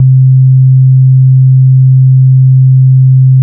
sine.wav